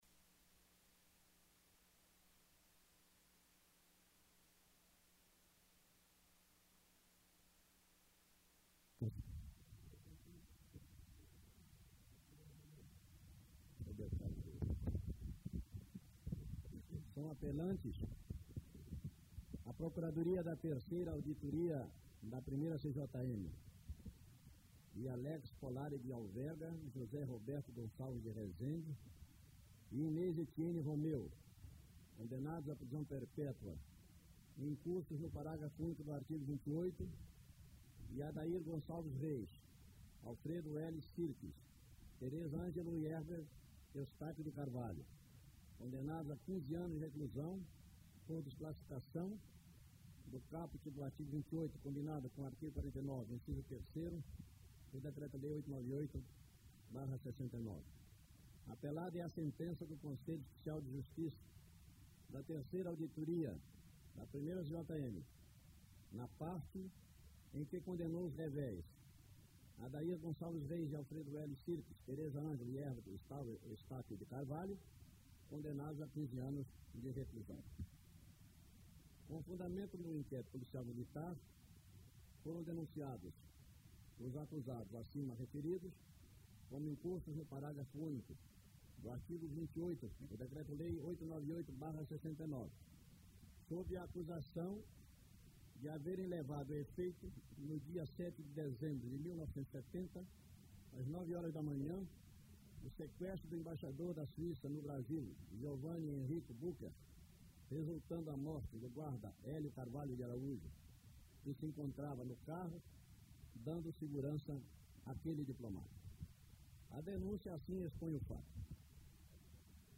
Áudios de sessão do Superior Tribunal Militar - Caso sequestro Embaixador da Suiça - Documentos Revelados
Os áudios em anexo, são de sessãao so STM, realizadas em 1977, para julgar apelação dos advogados dos presos políticos e outros indiciados que não foram presos.